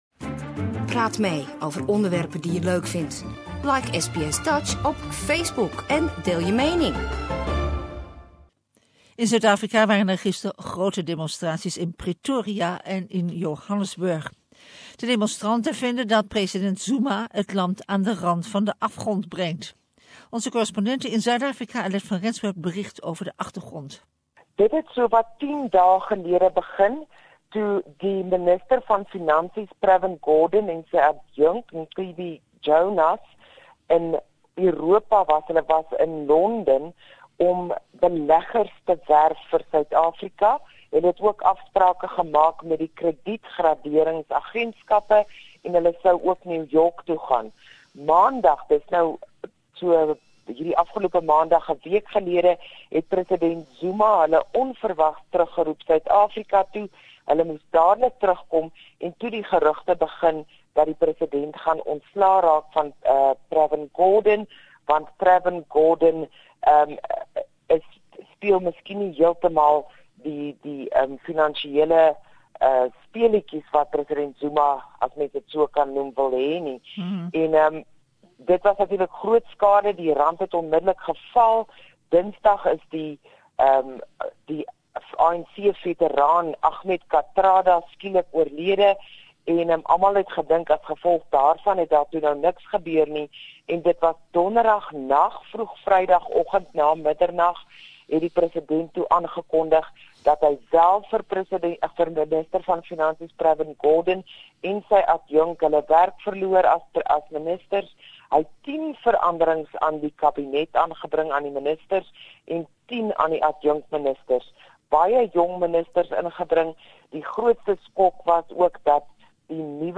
Big demonstrations in Pretoria and Jo'burg against the Zuma regime. Correspondent